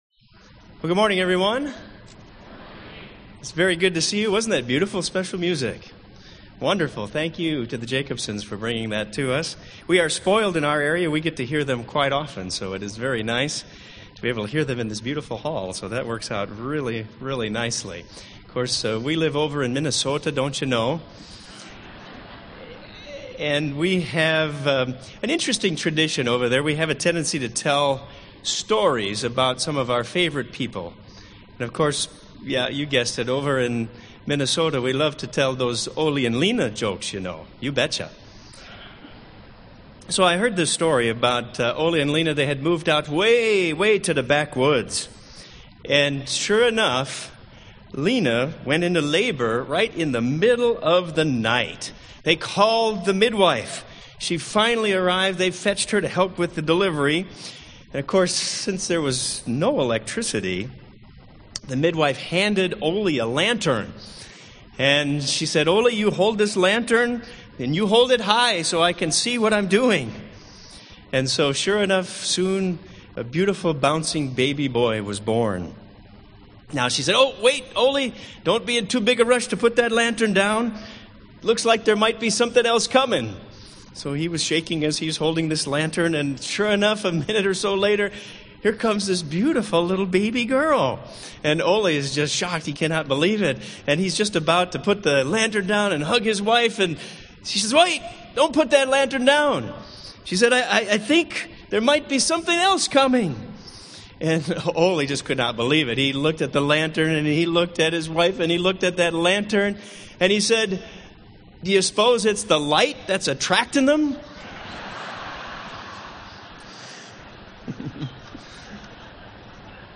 This sermon was given at the Wisconsin Dells, Wisconsin 2009 Feast site.